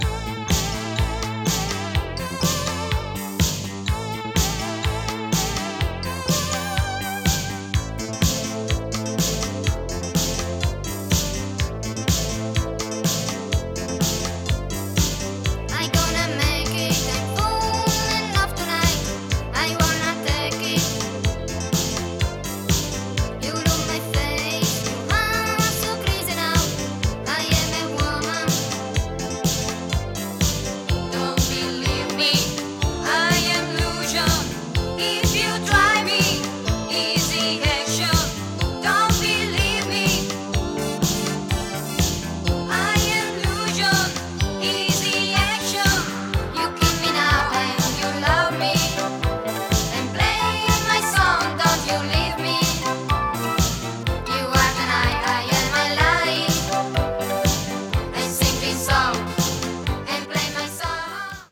最高なイタロ・ディスコ秘宝です。